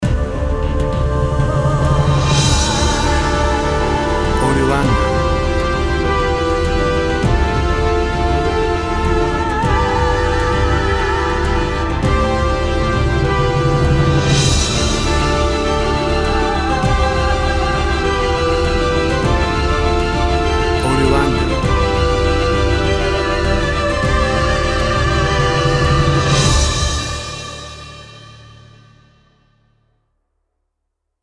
Epic music around the conquest and victory.
Tempo (BPM) 100